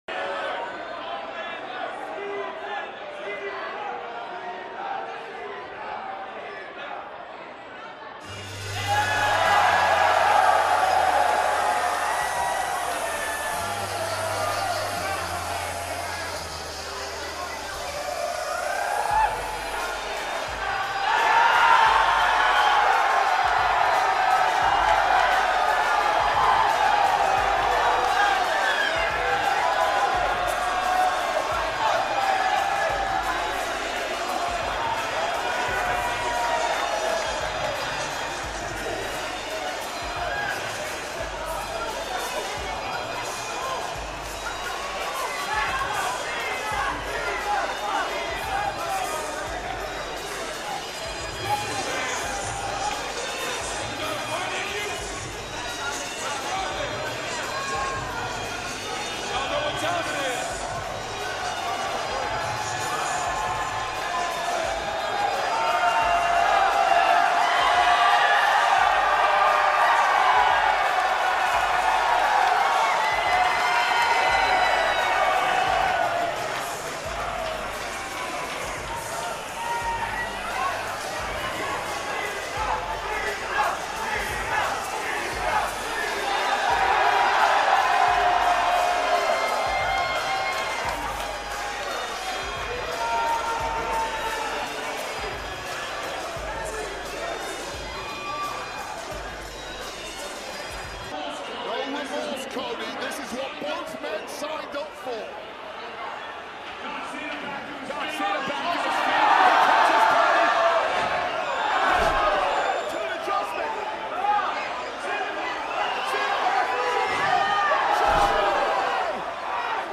WWE Fans Reaction To Brock Lesnar RETURN At WWE SummerSlam 2025 Night 2!